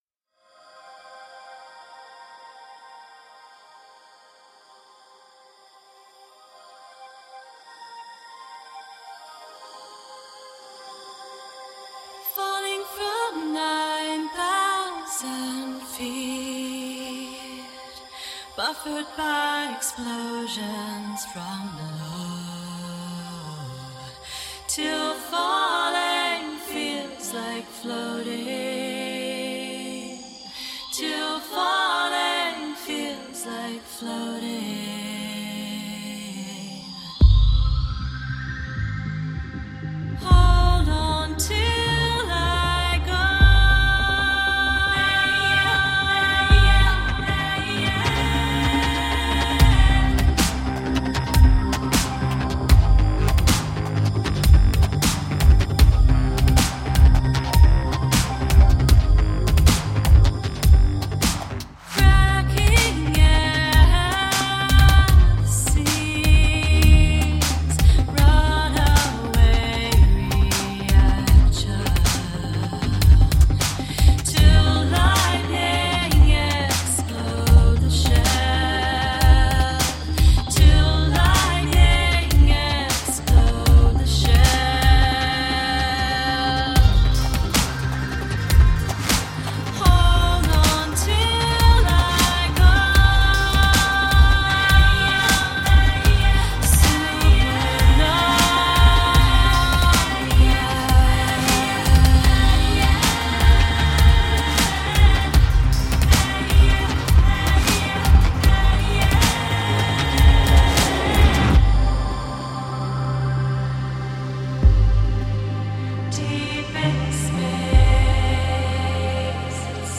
Alternative / downtempo / electro-pop.